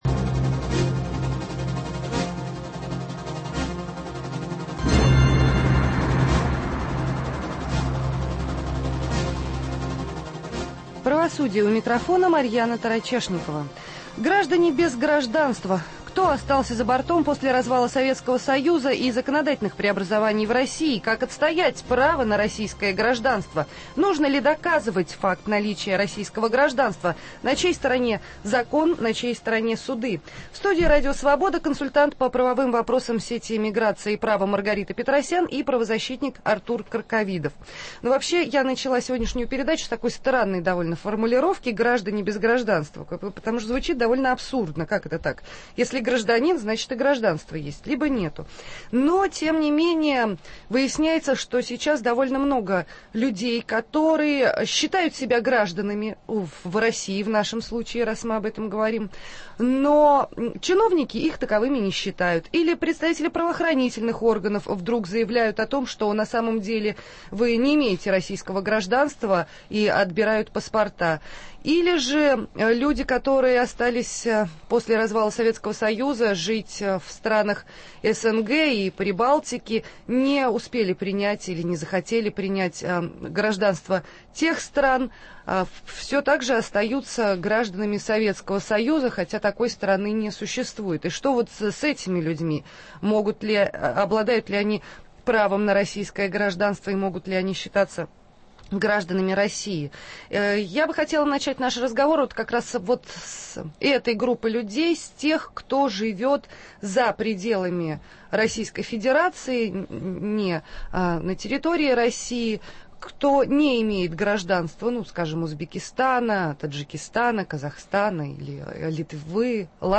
В студии РС